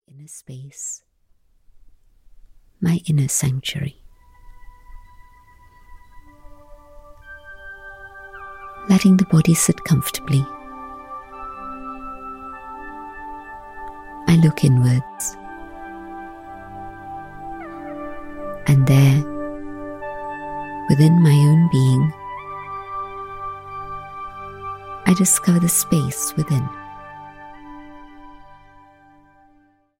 Guided meditations to calm the mind and spirit